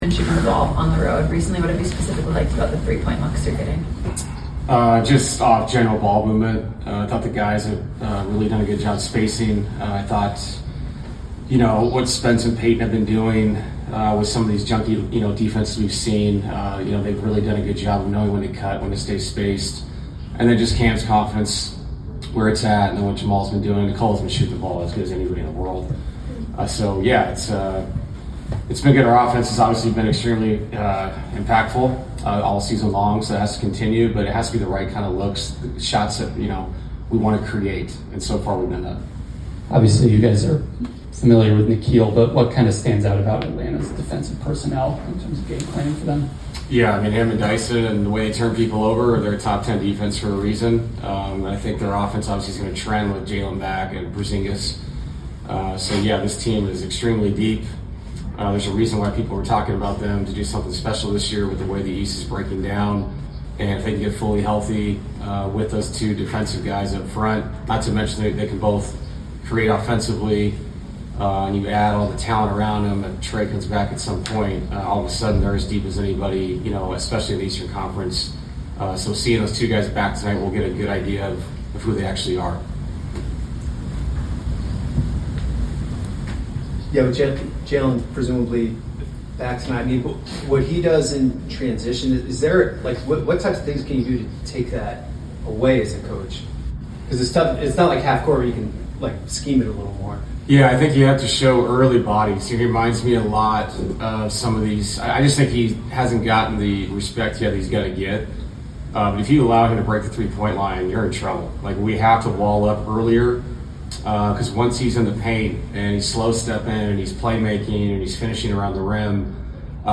Denver Nuggets Coach David Adelman Pregame Interview before taking on the Atlanta Hawks at State Farm Arena.